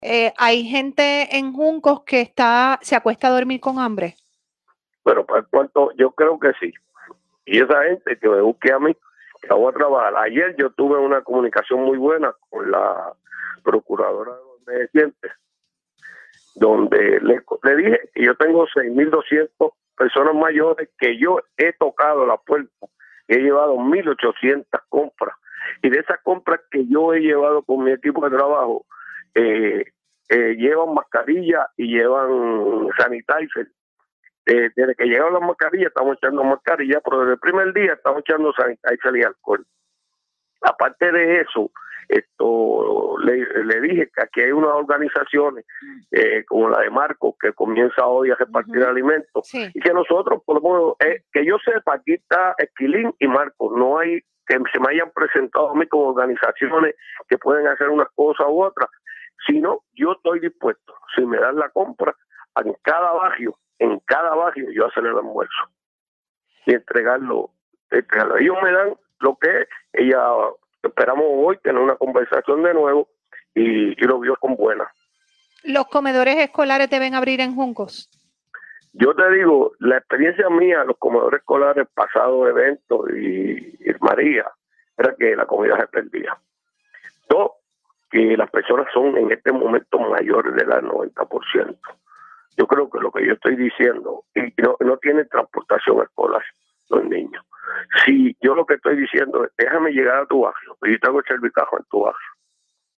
audio-alcalde-juncos.mp3